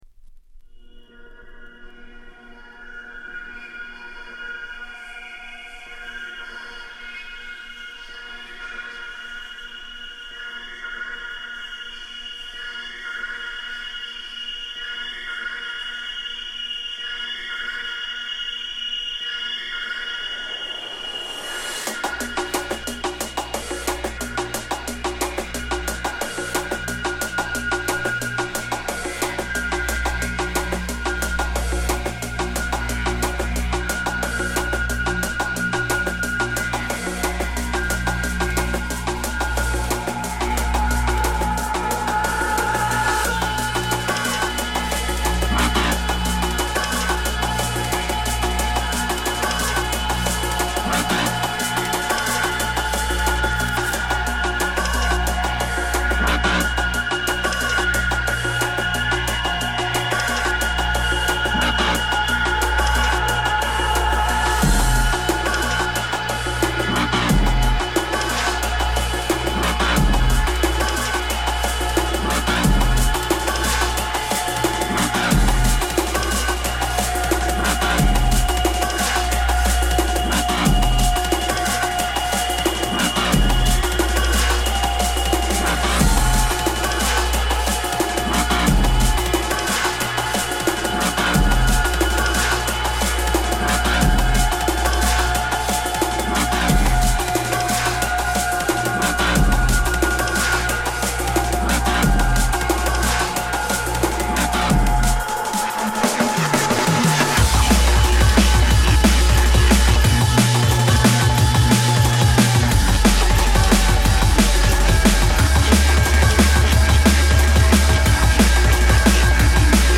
dnb demo
jungle / drum´n´bass